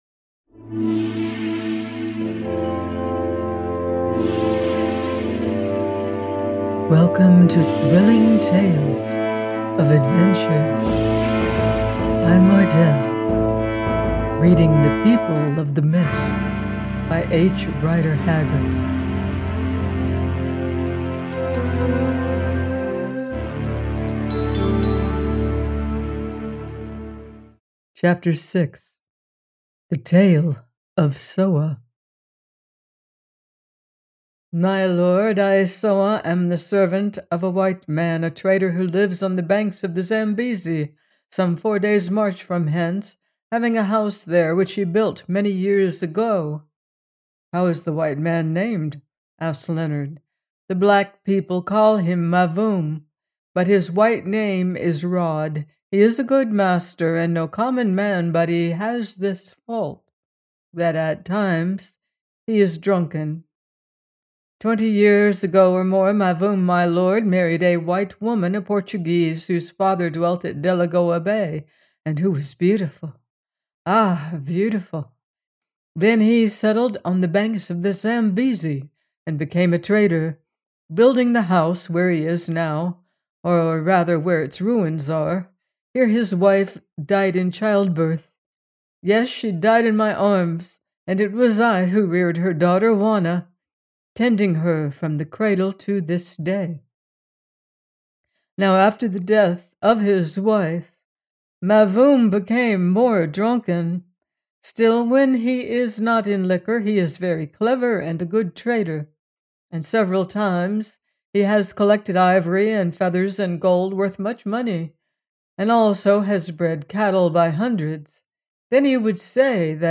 The People of the Mist – 6: by H. Rider Haggard - audiobook